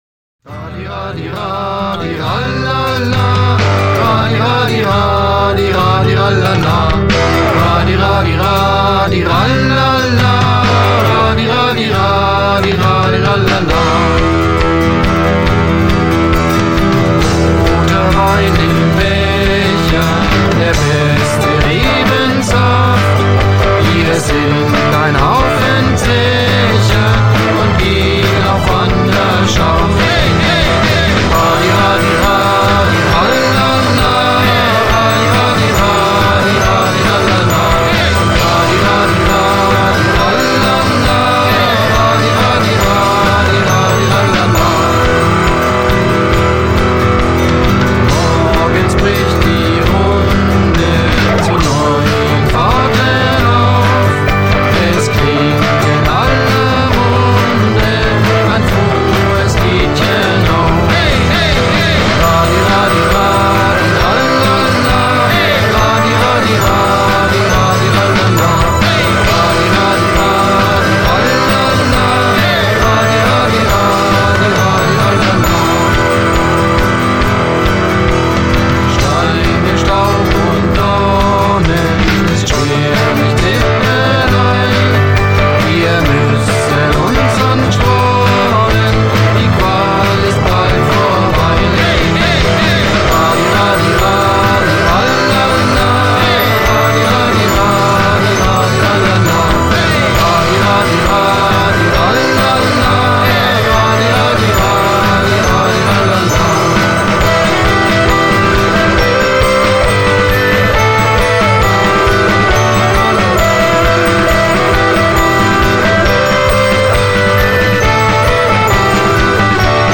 Rockinterpretation